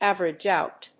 áverage óut